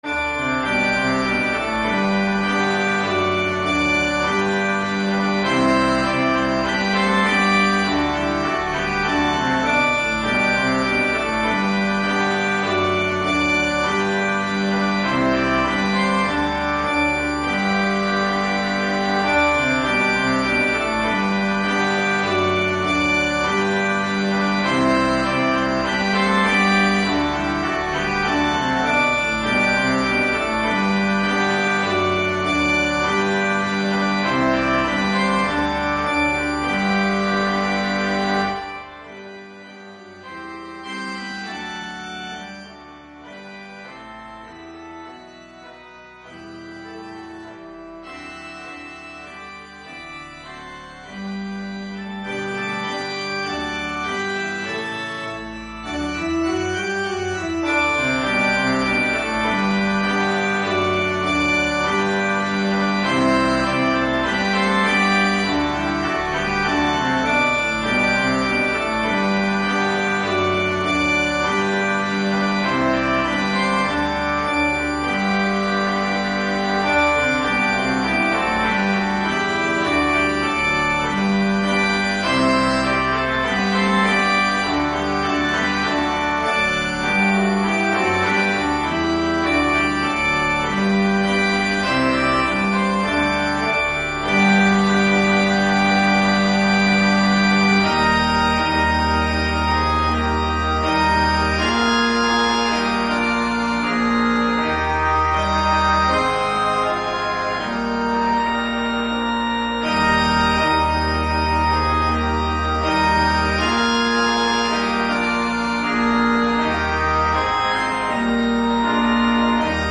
für Blechbläserquartett
Ensemblemusik für 4 Blechbläser